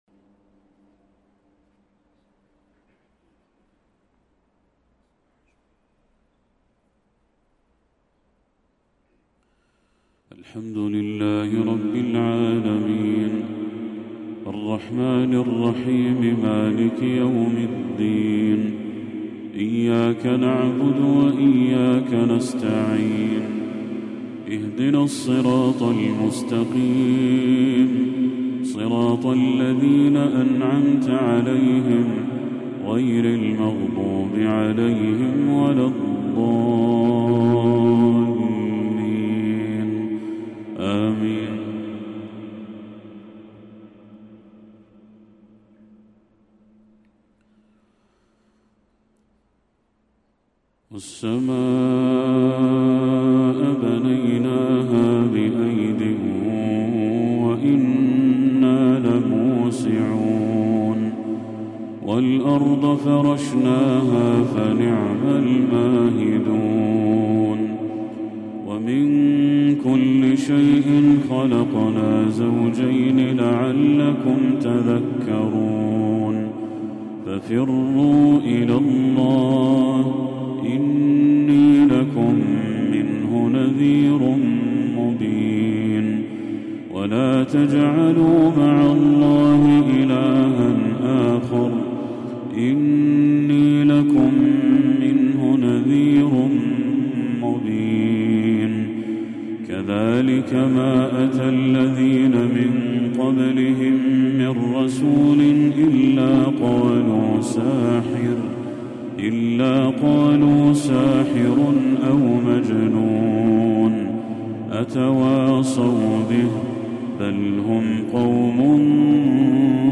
تلاوة لخواتيم سورة الذاريات للشيخ بدر التركي | مغرب 23 ربيع الأول 1446هـ > 1446هـ > تلاوات الشيخ بدر التركي > المزيد - تلاوات الحرمين